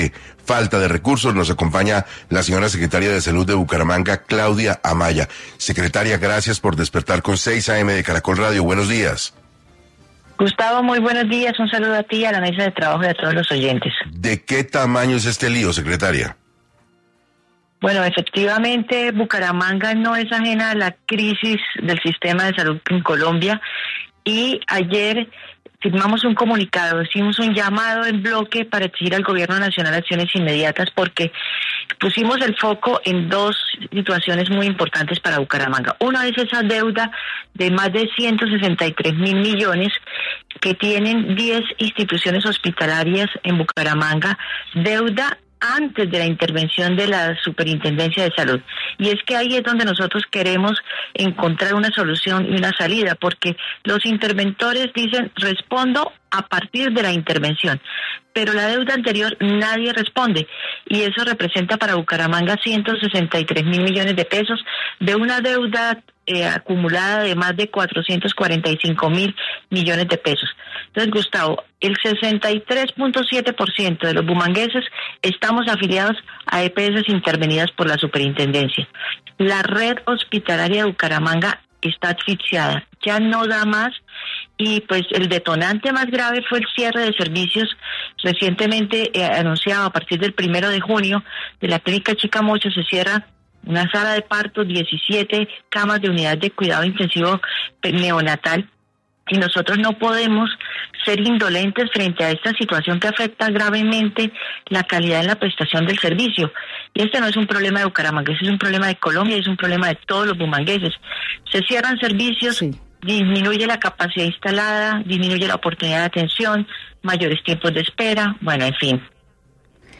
Claudia Amaya, secretaria de Salud de Bucaramanga, habló en 6AM acerca de la crisis financiera que tienen las IPS y las afectaciones que esto trae.